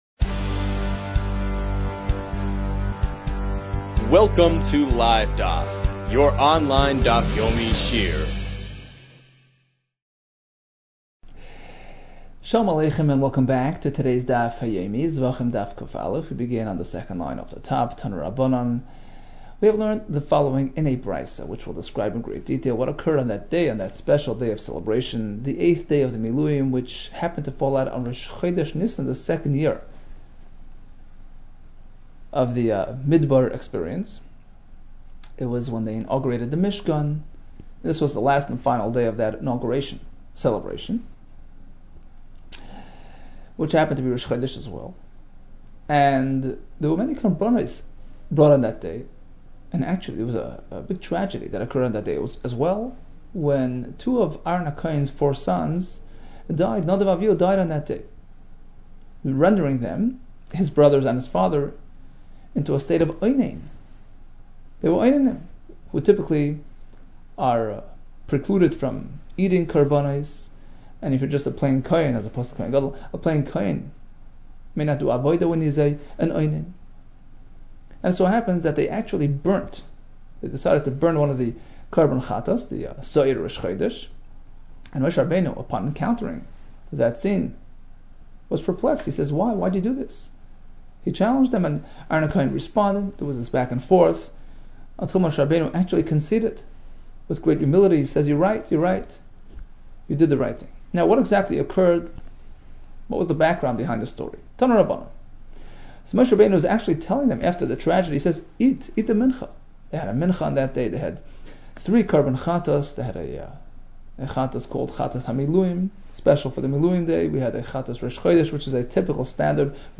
Zevachim 100 - זבחים ק | Daf Yomi Online Shiur | Livedaf